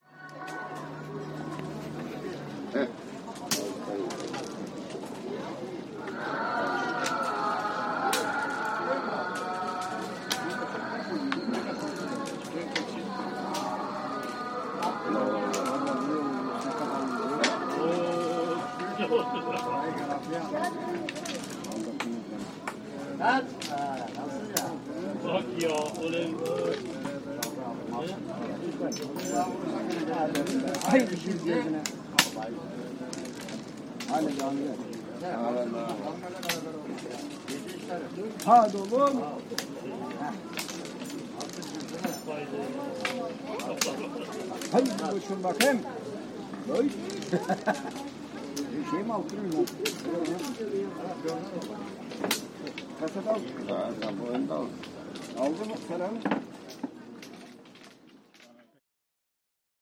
Backgammon players in Bodrum, Turkey